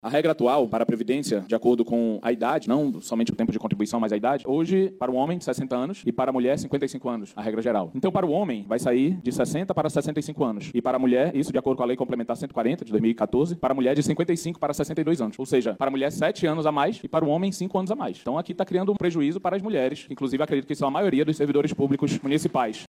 Durante a Sessão, o vereador Rodrigo Guedes, do Progressistas, questionou a proposta de idade mínima de aposentadoria que estipula dois anos a mais de trabalho para as mulheres.